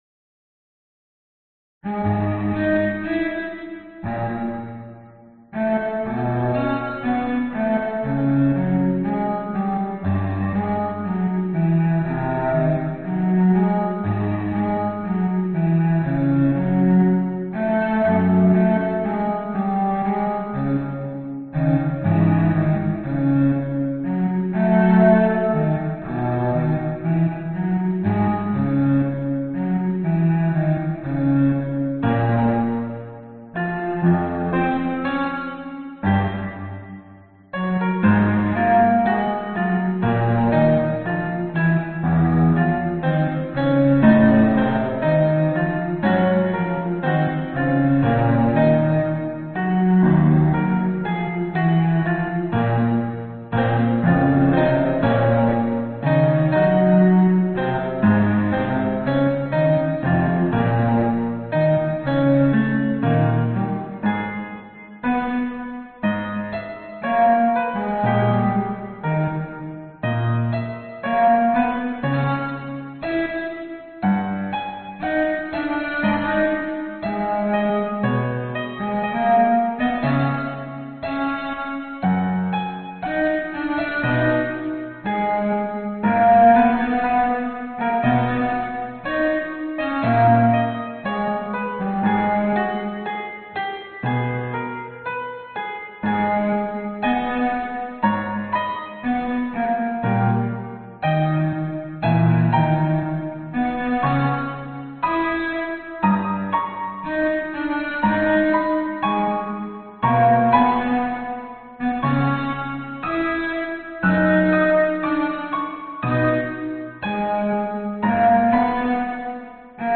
钢琴和大提琴奏鸣曲）。6/4, 154 bpm.